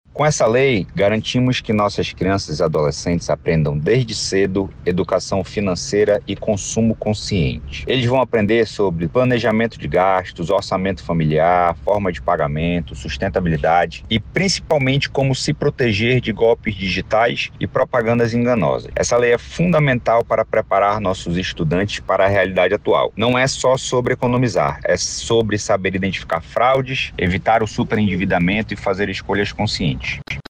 SONORA-VEREAOR-1.mp3